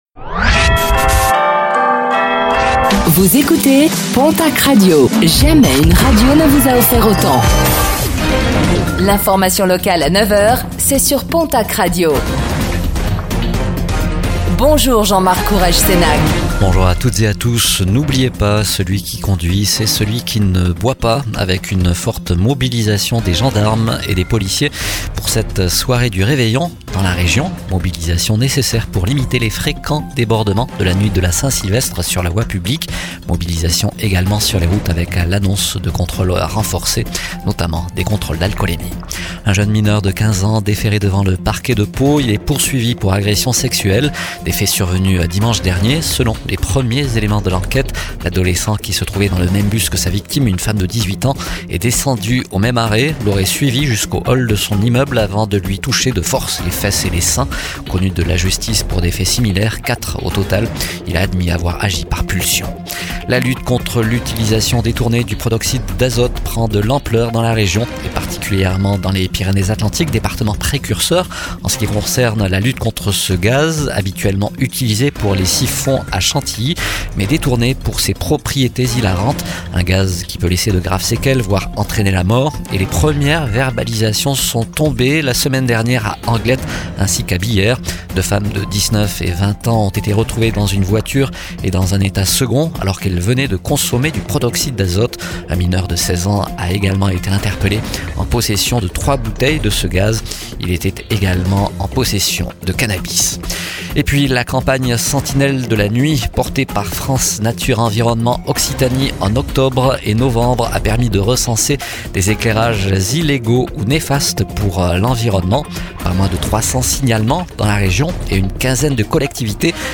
Réécoutez le flash d'information locale de ce mercredi 31 décembre 2025